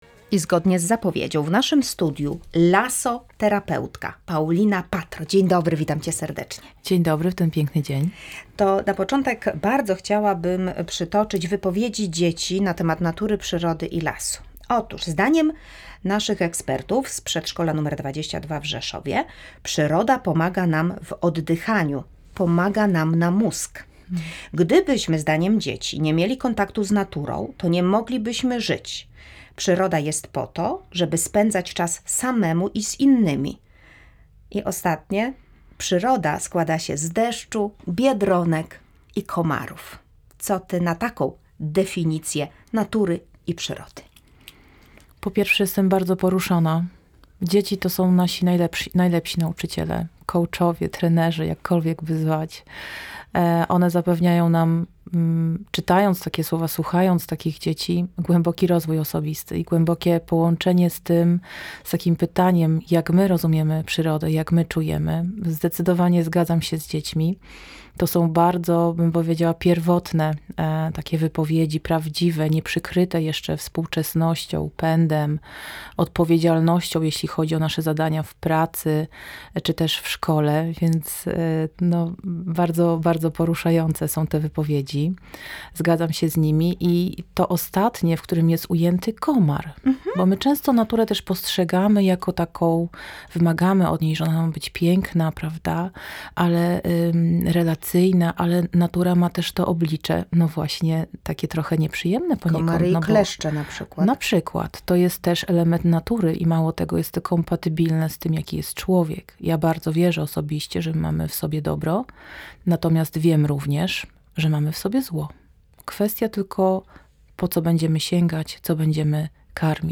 W rozmowie również o oryginalnym pomyśle naszego gościa na kreatywną zabawę karcianą dla dzieci która pomaga zbliżyć się do przyrody.\